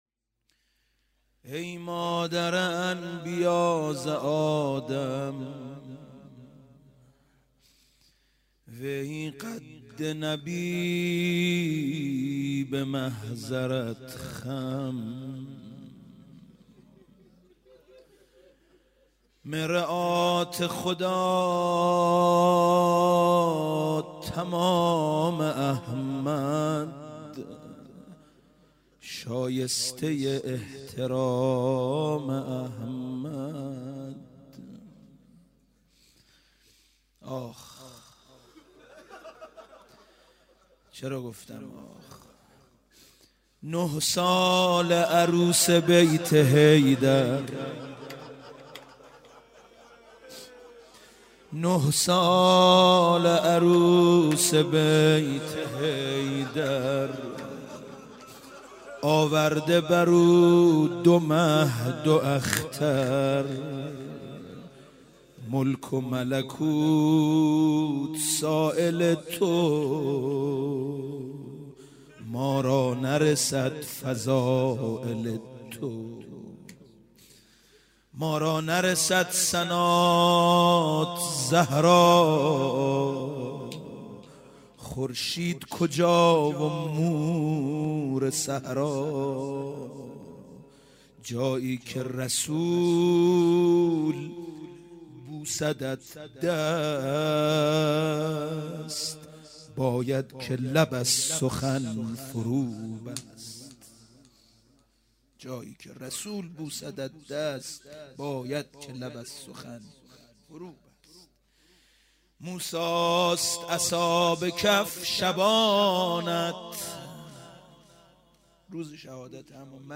روضه حضرت زهرا